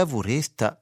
a furesta [ v ] : sonore